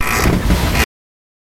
Bed Squeaks
Bed Squeak Rattle